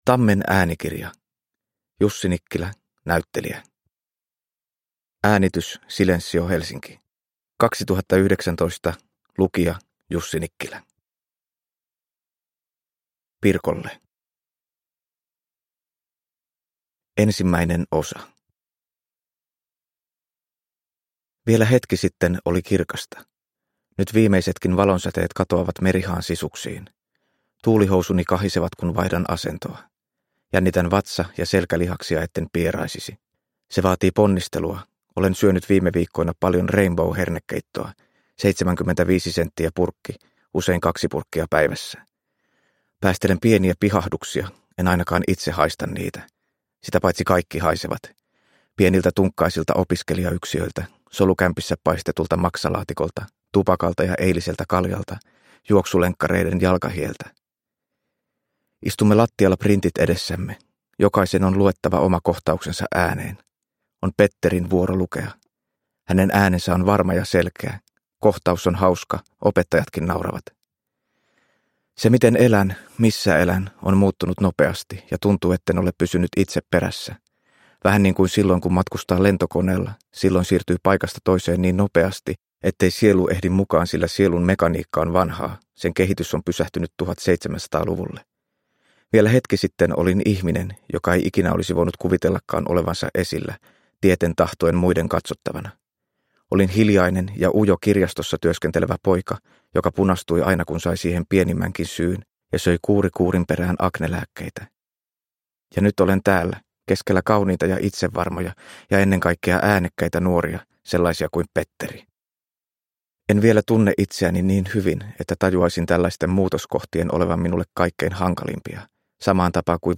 Näyttelijä – Ljudbok – Laddas ner